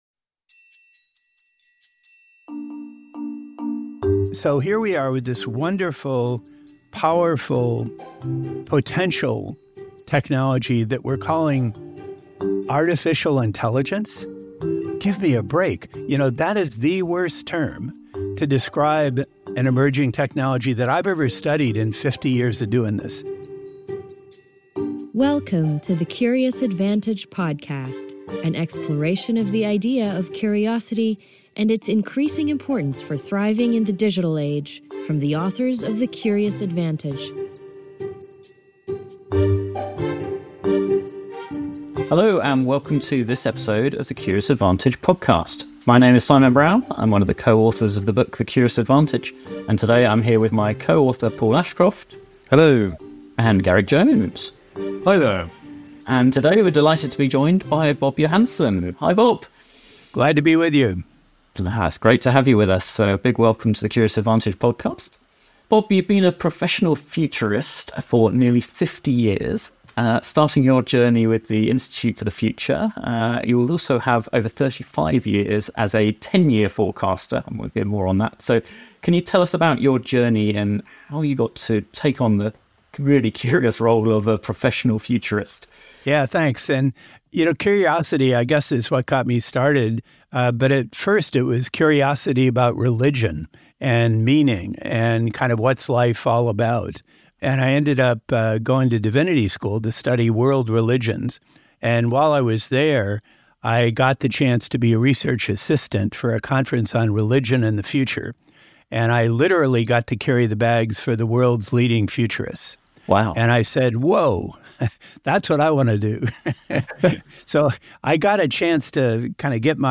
this conversation offers a hopeful yet grounded roadmap for staying curious in the face of the unknown